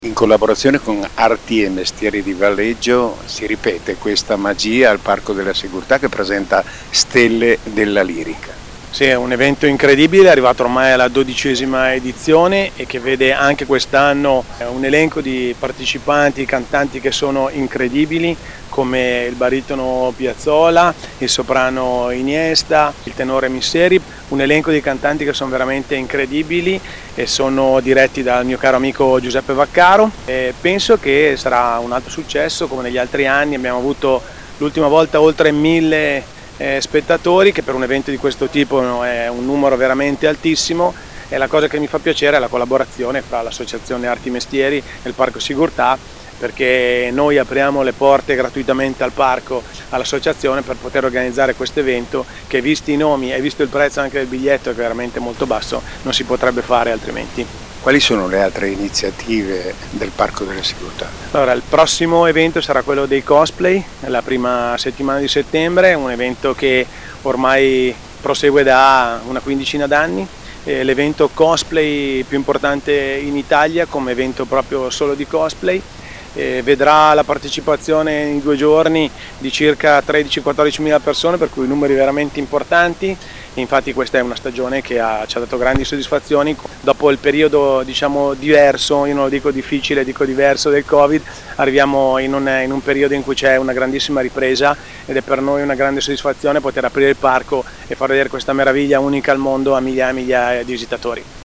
Le interviste del nostro corrispondente